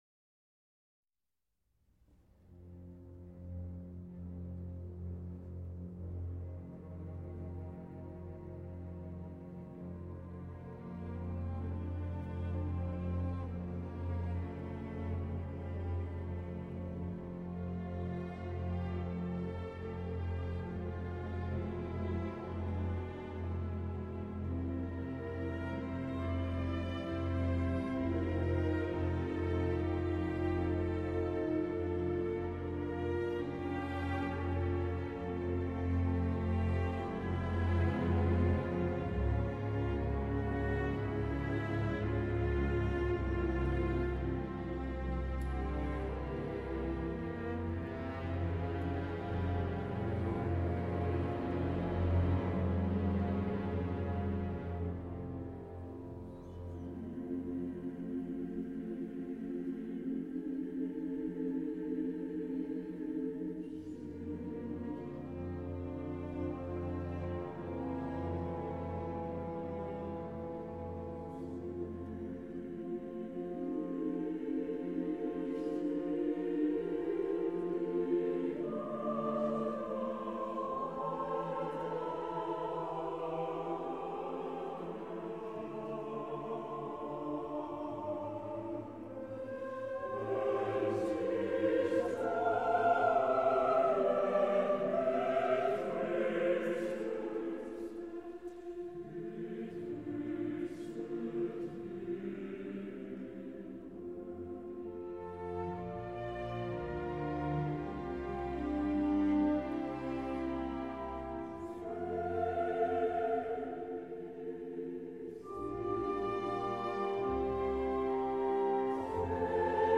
Choir
A musical ensemble of singers.